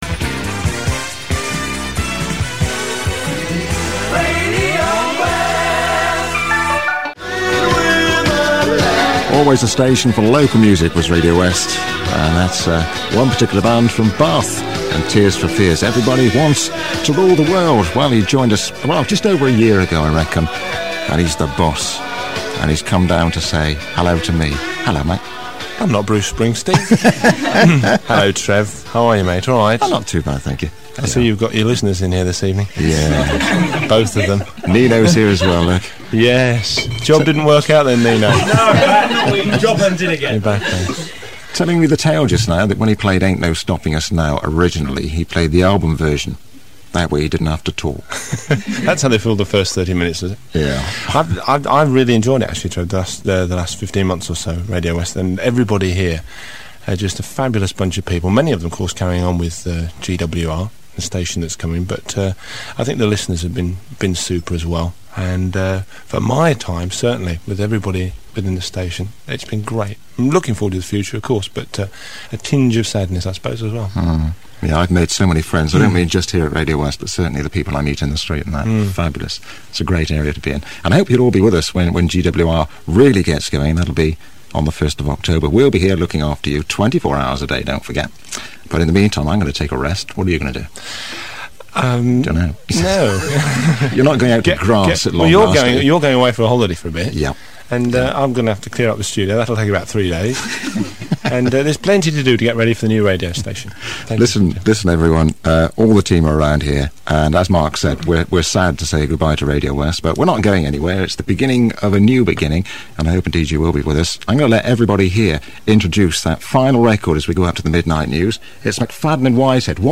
Radio West closedown - 1985